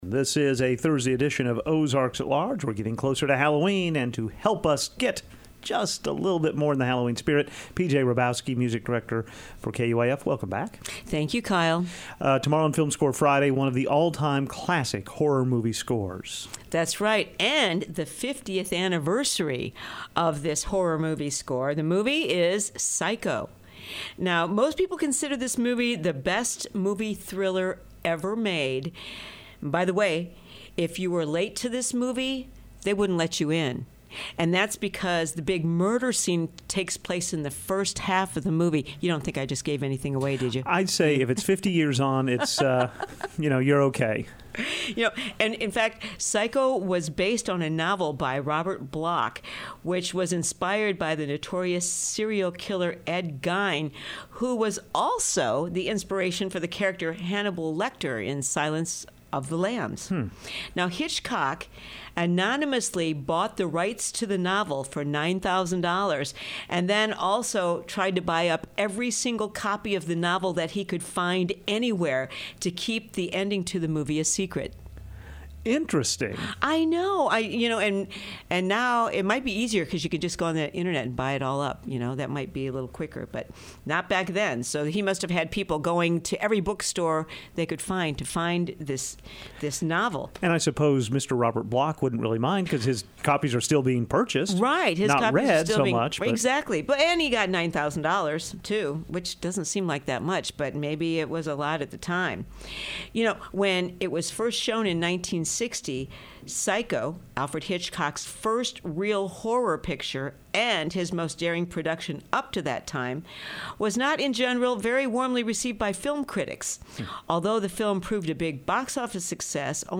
film score conversation